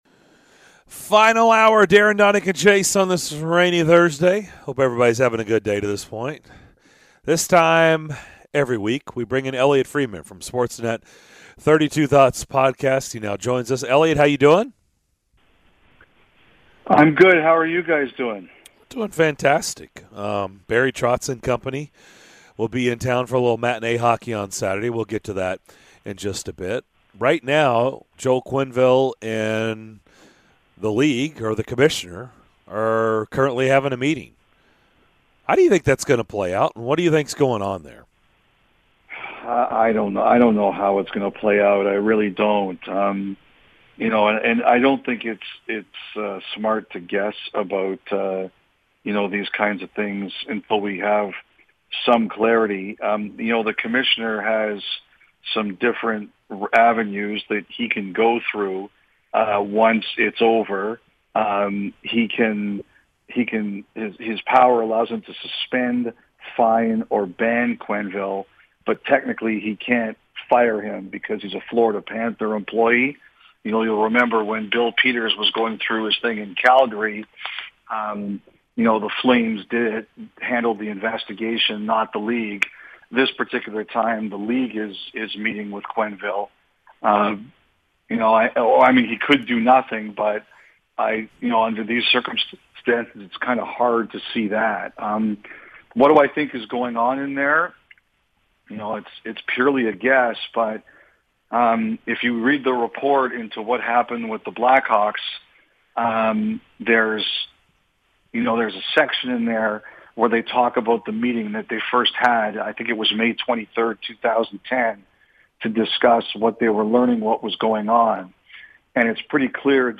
Sportsnet's Elliotte Friedman joined the show to discuss the fallout from the Chicago Blackhawks scandal.